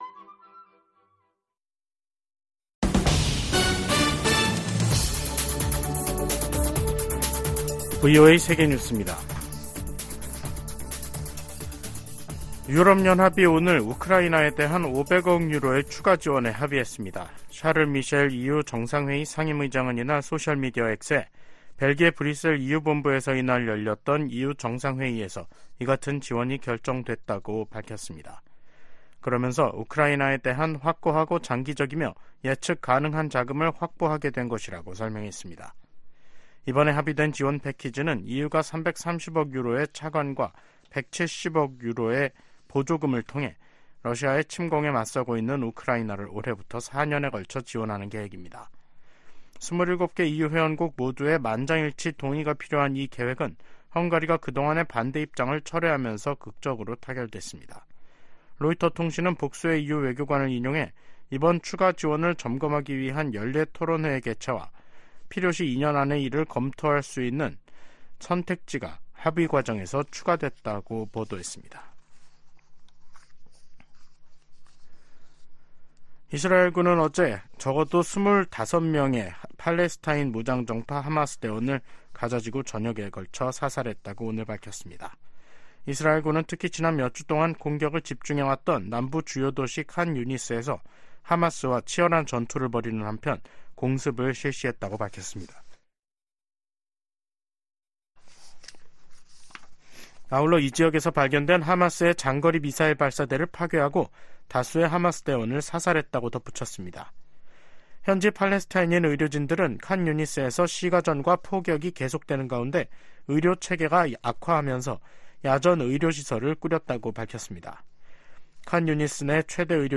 생방송 여기는 워싱턴입니다 2024/2/1 저녁
세계 뉴스와 함께 미국의 모든 것을 소개하는 '생방송 여기는 워싱턴입니다', 2024년 2월 1일 저녁 방송입니다. '지구촌 오늘'에서는 유럽연합(EU)이 우크라이나 추가 지원안을 승인한 소식 전해드리고, '아메리카 나우'에서는 연방준비제도가 기준금리를 재차 동결한 이야기 살펴보겠습니다.